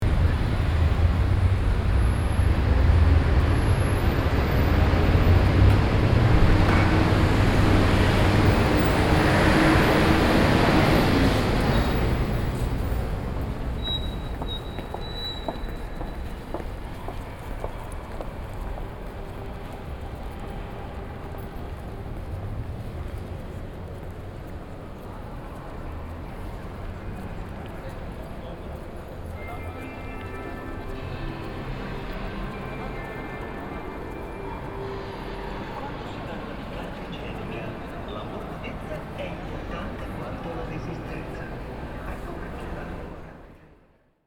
Rumore
Fuori e dentro l'atrio della stazione di Porta Nuova Torino, stazione ferroviaria di Porta Nuova
Microfoni binaurali stereo SOUNDMAN OKM II-K / Registratore ZOOM H4n